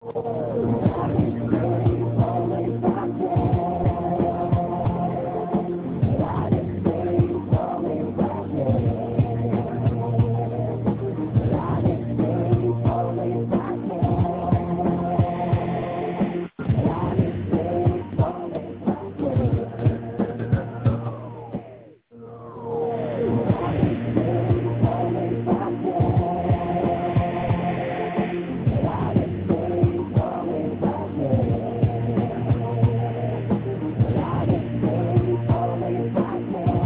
Послушал: звук как из унитаза, ничего непонятно... поют вроде: "радостей веселых побед" или это показалось...kiss